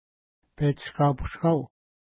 Petshishkapishkau Next name Previous name Image Not Available ID: 558 Longitude: -60.1351 Latitude: 53.8175 Pronunciation: petʃəska:pəska:w Translation: Unknown Official Name: Mokami Hill Feature: mountain